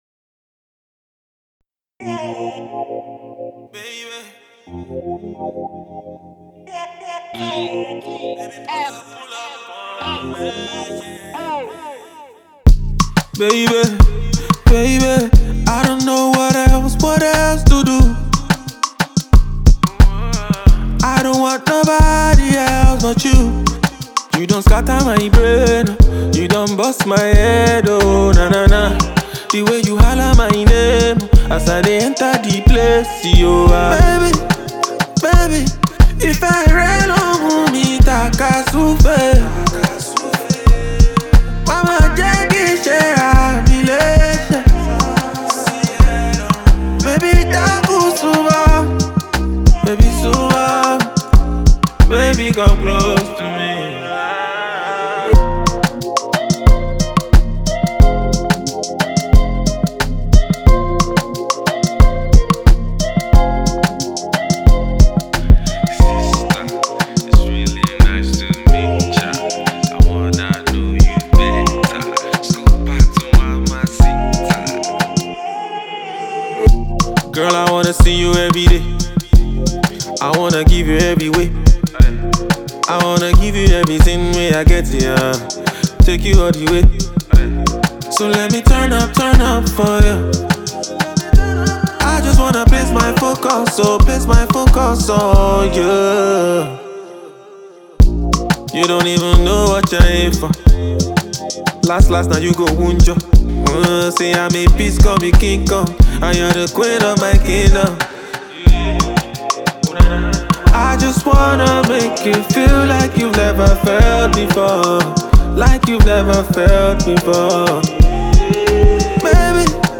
sweet & melodious love anthem
This genius take at a love song was produced by producer